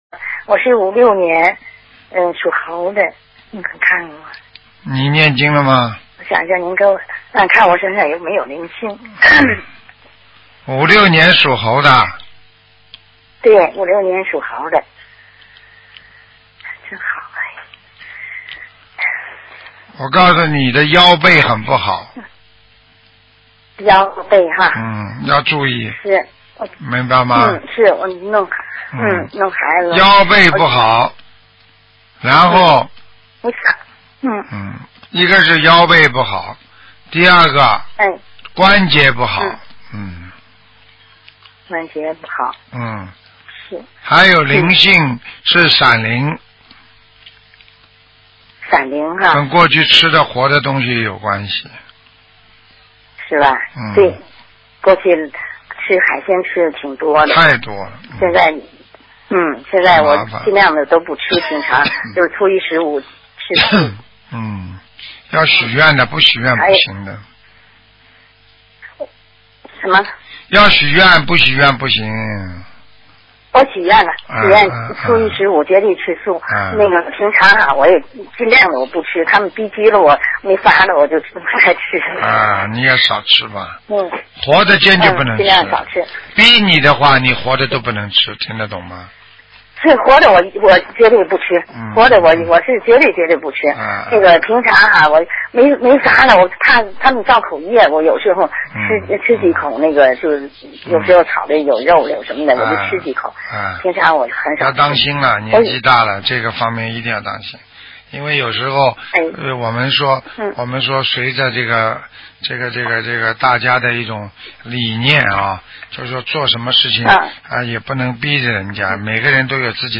目录：2014年_剪辑电台节目录音集锦